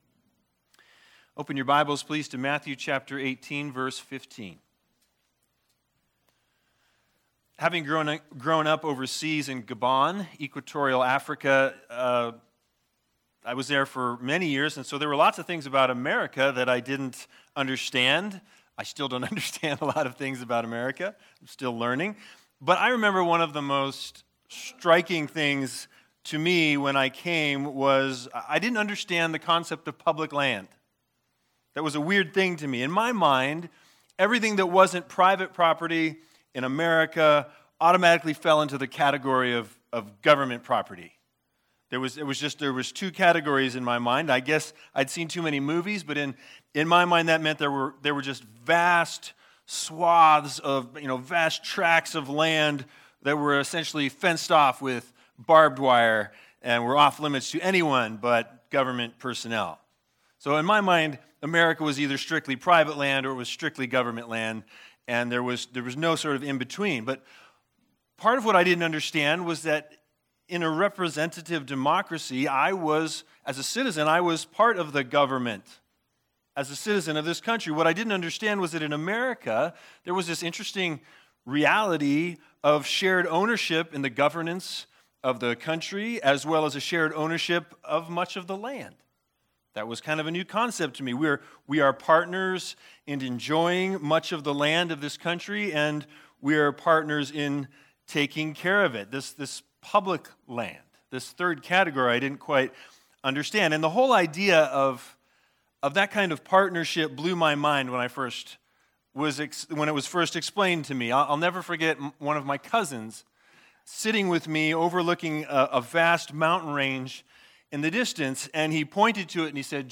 Matthew 18:15-20 Service Type: Sunday Sermons The Big Idea